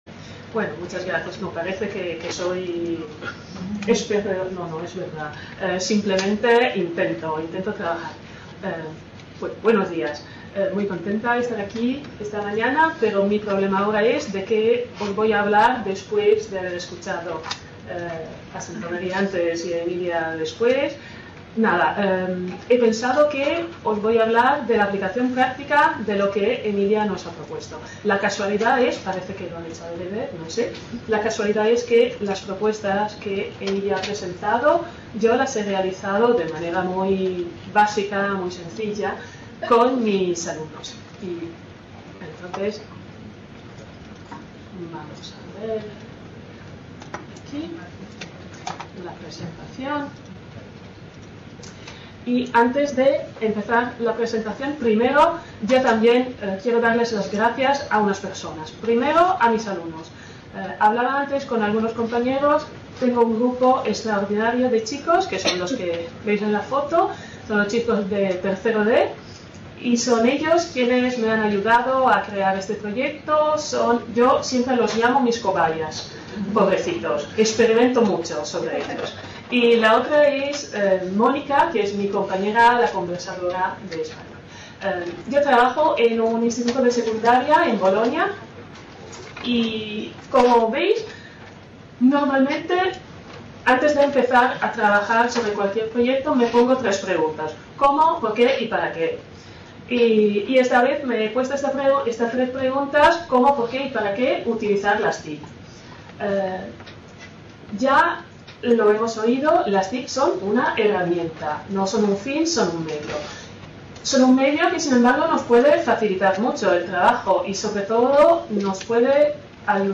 Description Jornadas Internacionales sobre el uso de las TIC en la enseñanza del español como lengua extranjera | Red: UNED | Centro: UNED | Asig: Reunion, debate, coloquio...